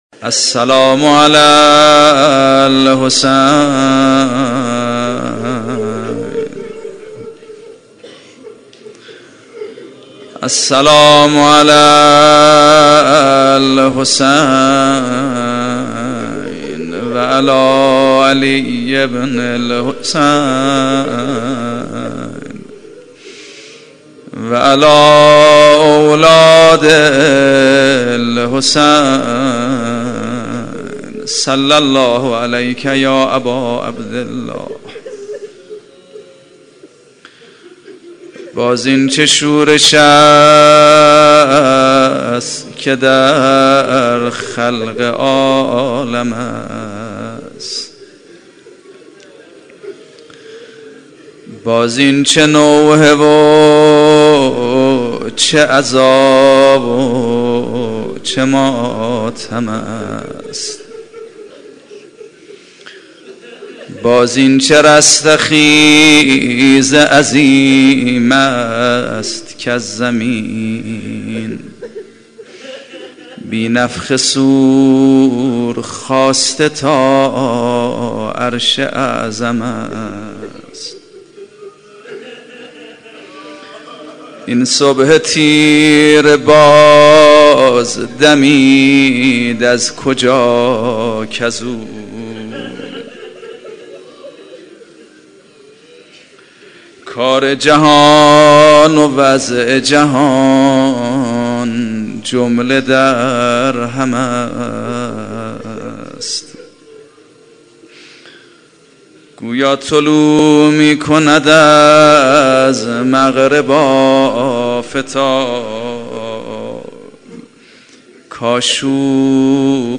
صوت/ روضه استقبال از محرم